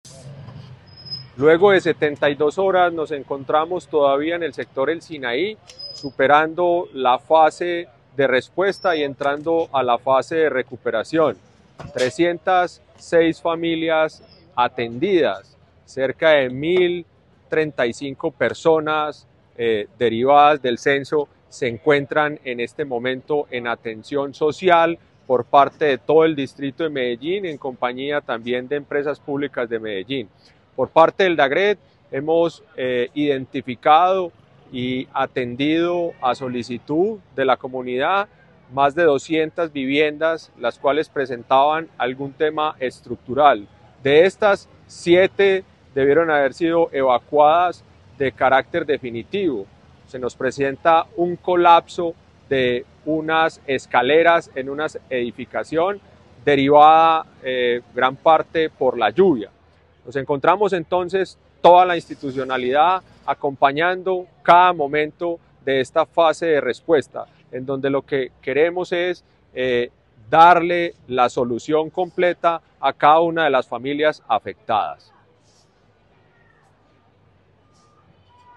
Palabras de Carlos Quintero, director del DAGRD